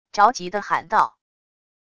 着急的喊道wav音频